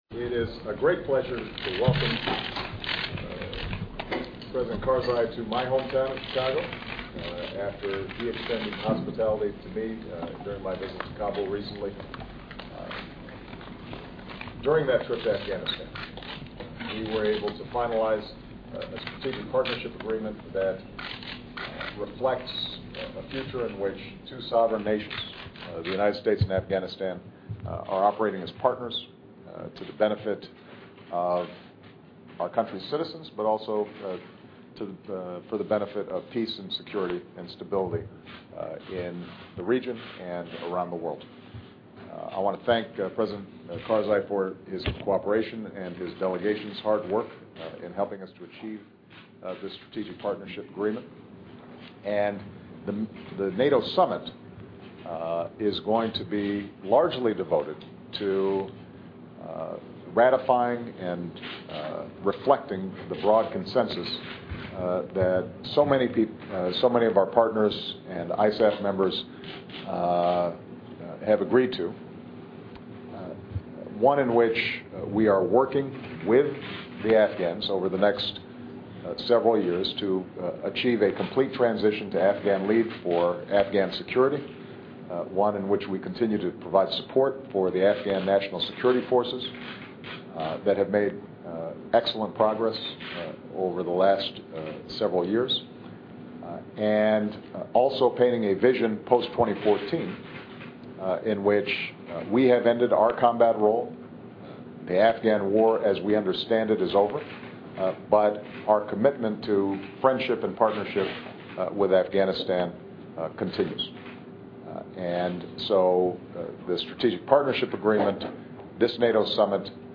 奥巴马总统每周电台演讲:总统赞赏卡尔扎伊总统并呼吁尽快实现战略合作 听力文件下载—在线英语听力室